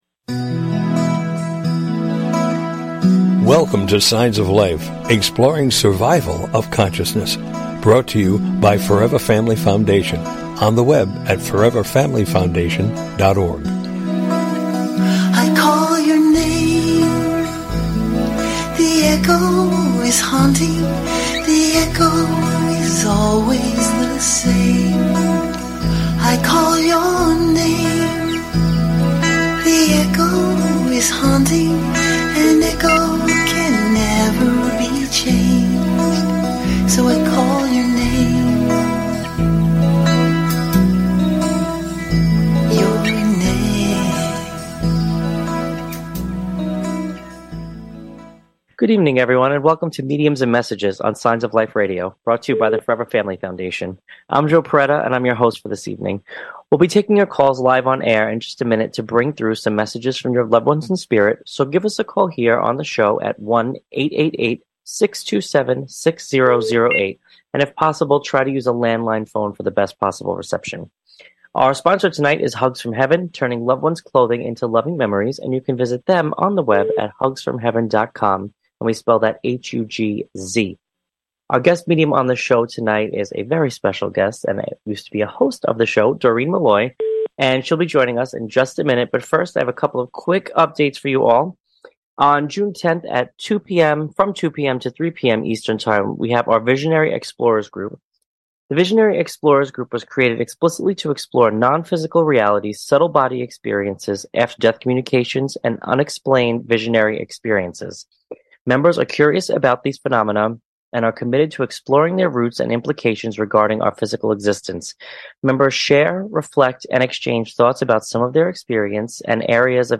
Talk Show Episode
This Show Format invites listeners to call in for "mini readings."